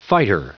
Prononciation du mot fighter en anglais (fichier audio)
Prononciation du mot : fighter